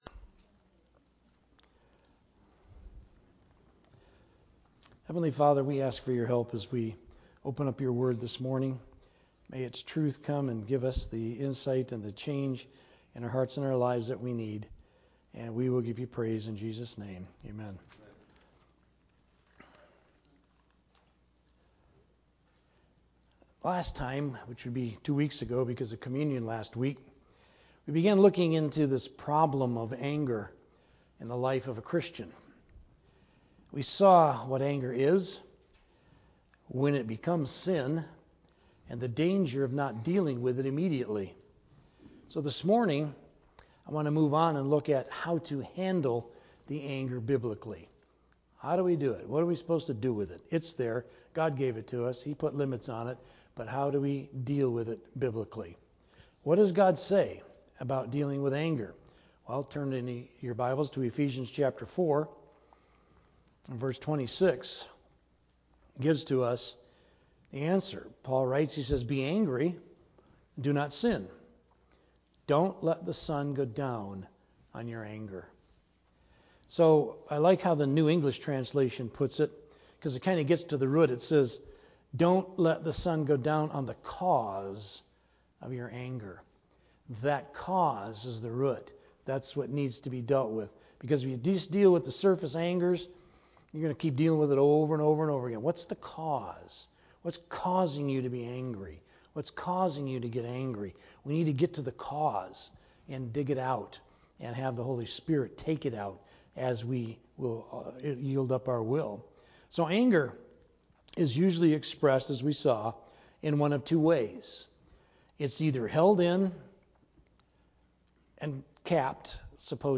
Related Topics: Sermon